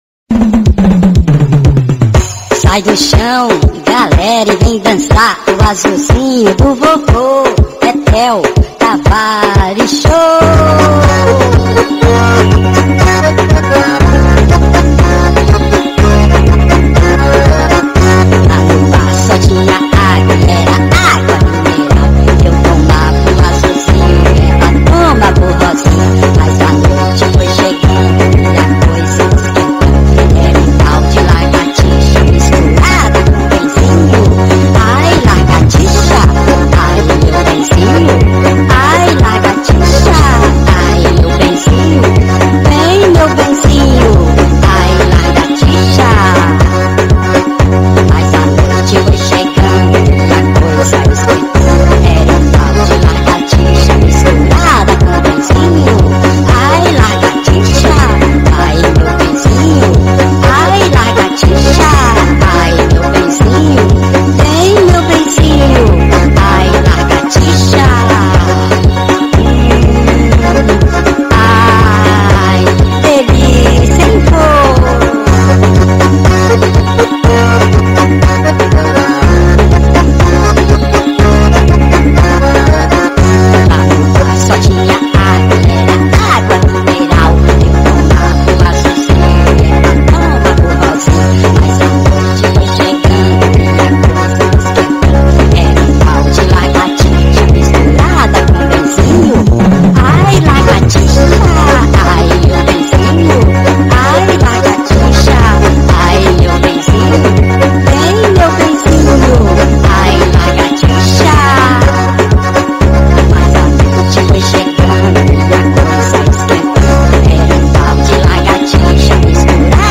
EstiloPop
Forró Pesado